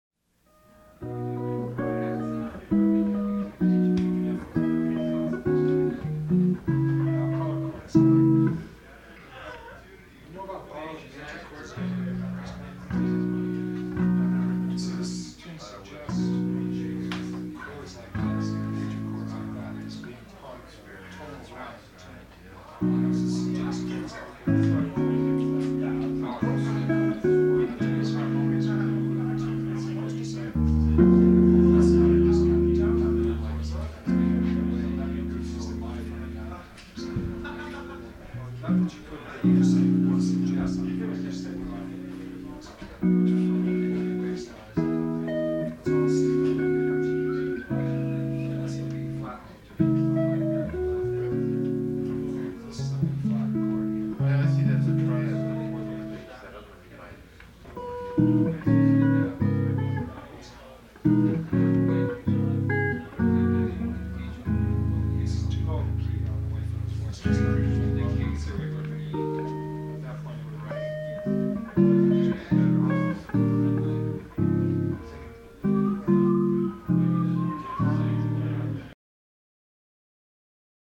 Ted Greene "Guitar Institute of Technology" Seminar - 1978